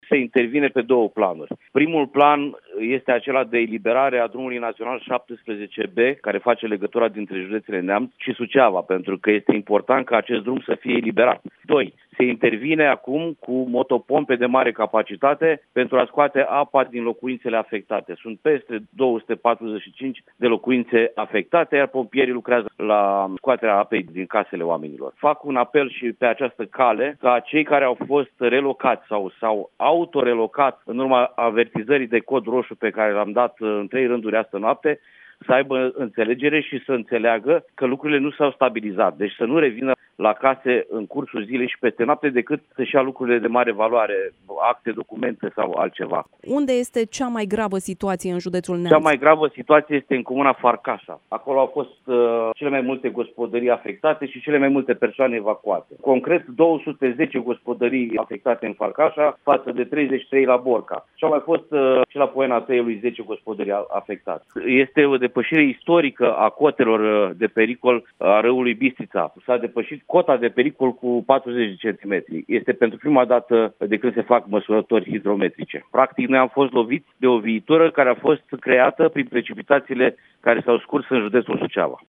Prefectul de Neamț, Adrian Bourceanu, a declarat că, în prezent, se acționează pentru degajarea unui drum național care face legătura între județele Neamț și Suceava și pentru evacuarea apelor din gospodării.